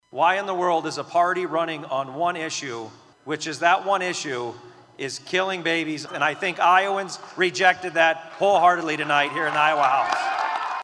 (CROWD FADE) ;10